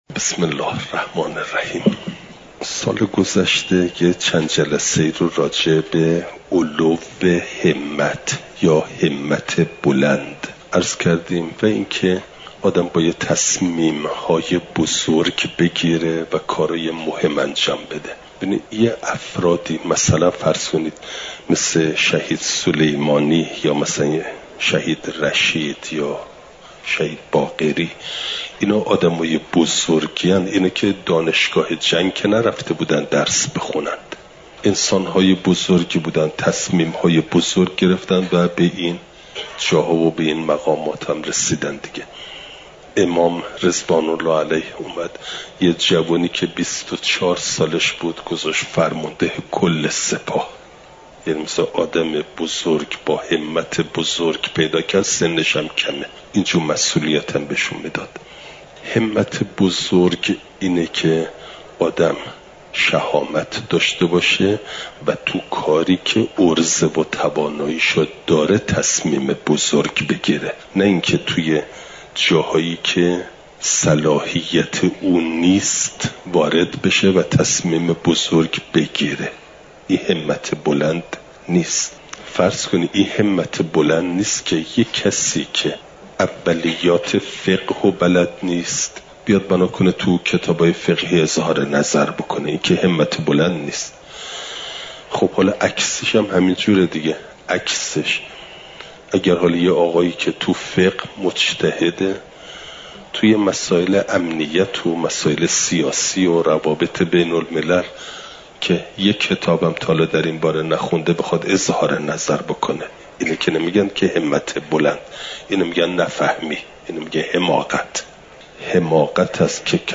چهارشنبه ۳۰ مهرماه ۱۴۰۴، حرم مطهر حضرت معصومه سلام ﷲ علیها